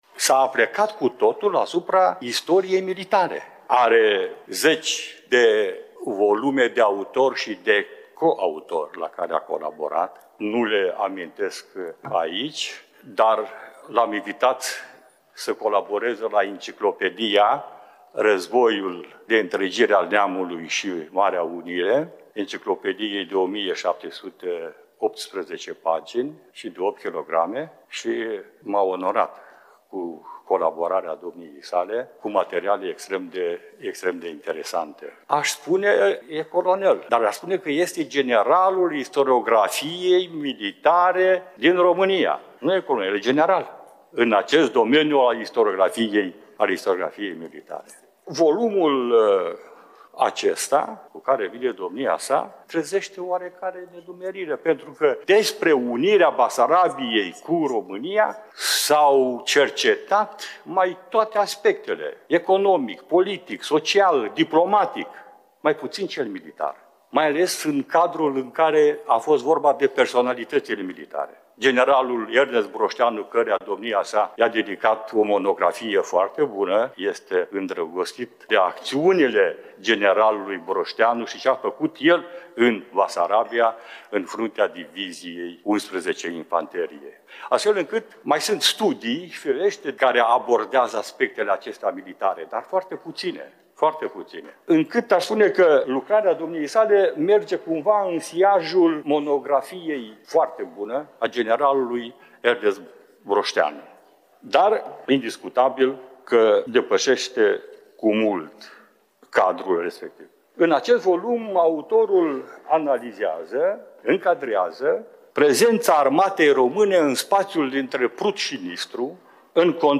Deschiderea conferinței a avut loc în ziua de joi, 4 septembrie a.c., începând cu ora 9, în Sala „Vasile Pogor” din incinta Primăriei Municipiului Iași.